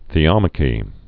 (thē-ŏmə-kē)